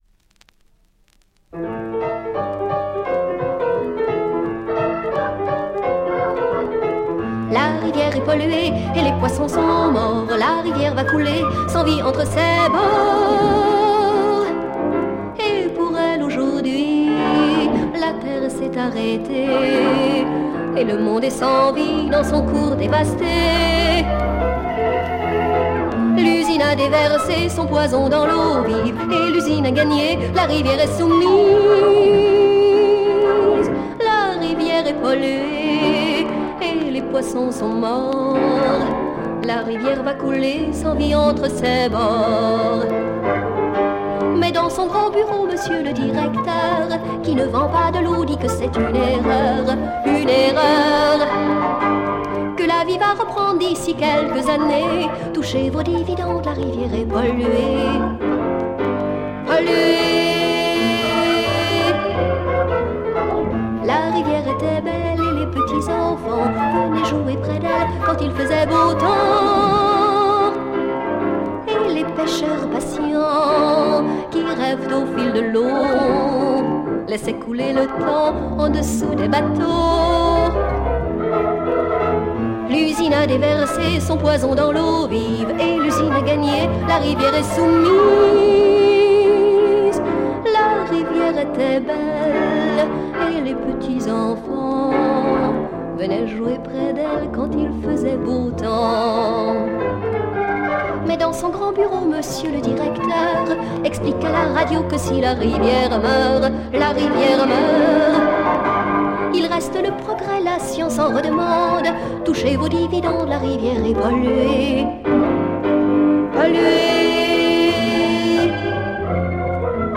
French female folk sike EP